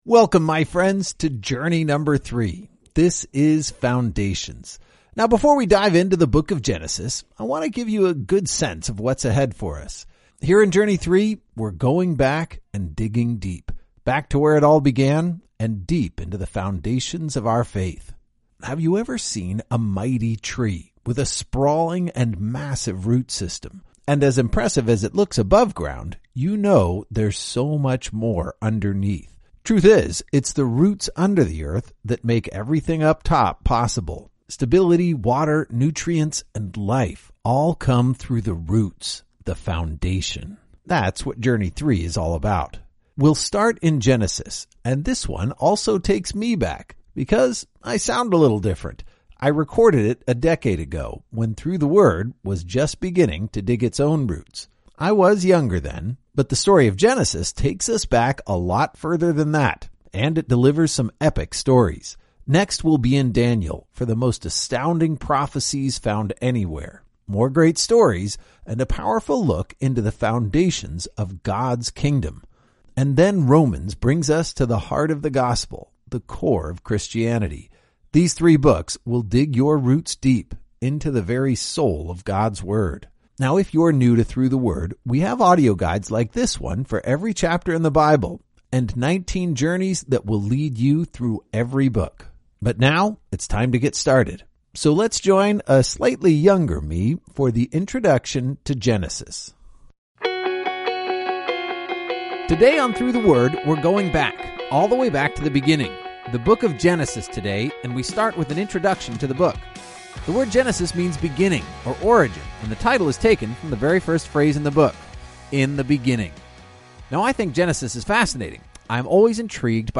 Each journey takes you on an epic adventure through several Bible books, as your favorite pastors clearly explain each chapter in under ten minutes. Journey #3 is Foundations, where Genesis takes us back to our origins, Daniel delivers phenomenal prophecies, and Romans lays out the heart of the gospel.